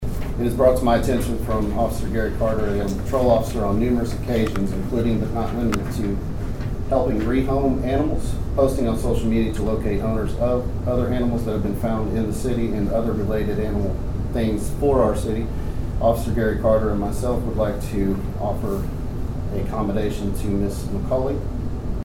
The Dewey City Council honored two police officers during the city council meeting at Dewey City Hall on Monday night.